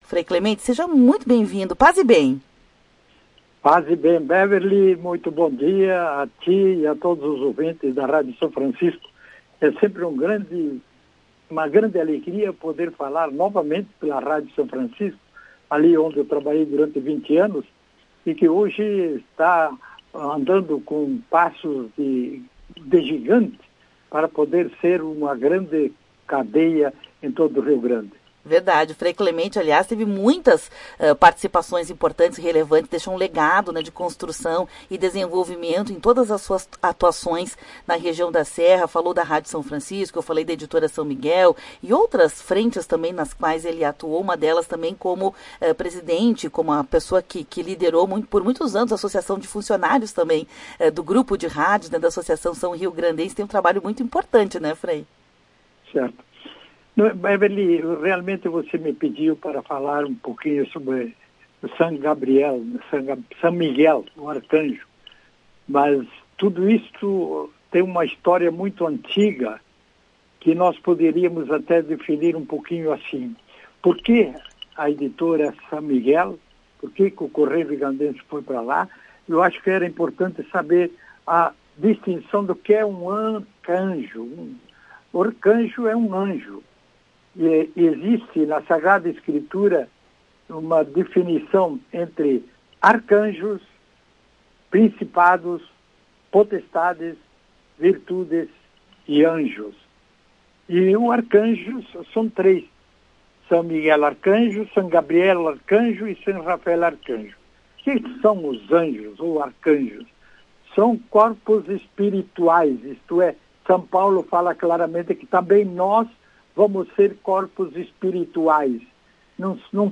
A entrevista foi ao Ar no programa Temática.